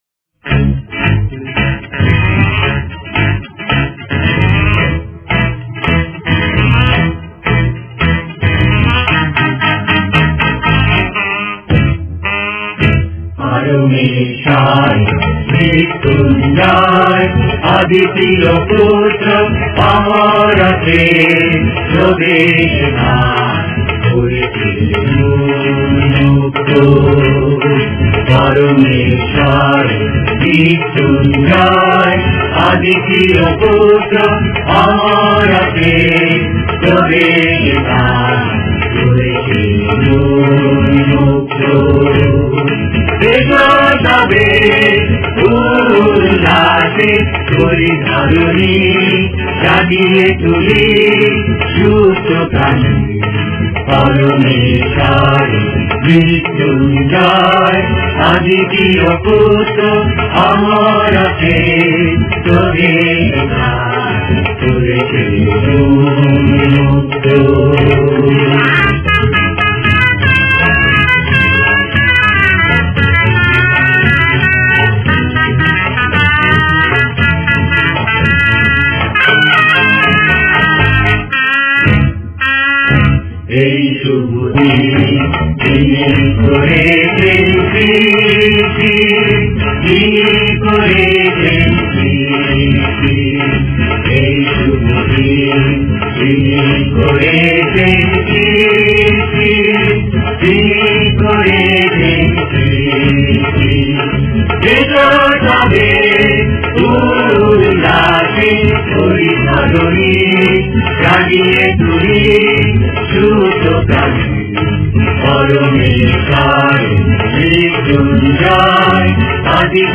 Directory Listing of mp3files/Bengali/Devotional Hymns/Good Friday/ (Bengali Archive)